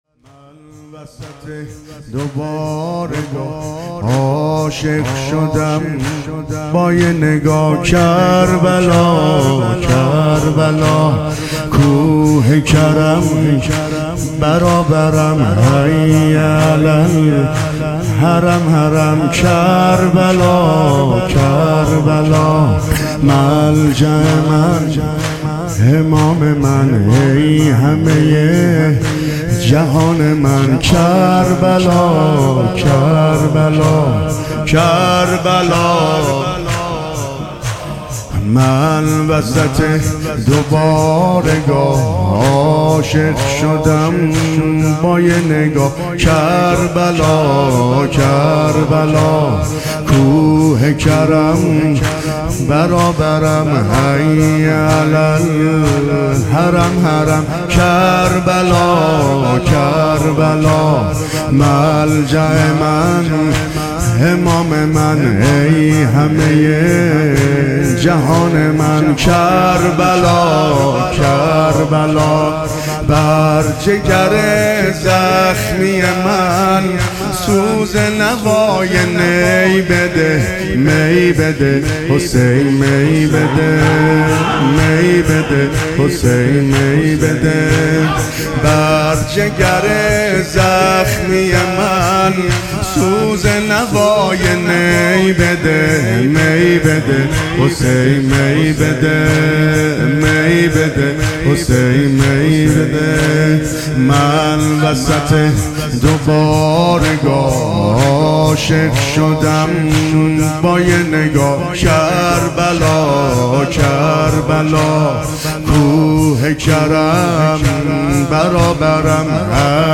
مداحی
شب اول محرم 1399 هیات رقیه جان سلام الله علیها